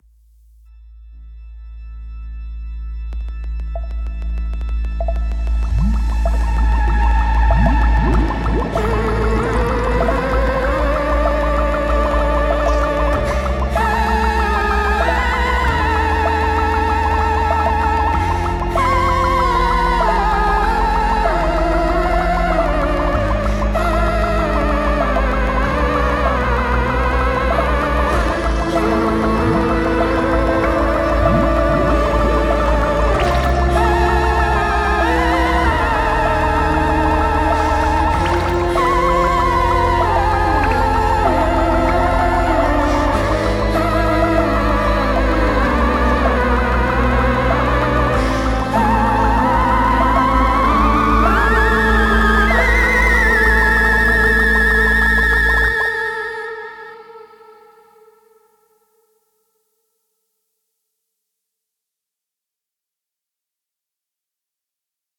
Hier drei Musikauszüge aus der aktuellen Inszenierung.